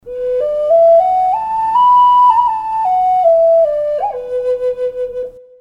Пимак B (high)
Пимак B (high) Тональность: B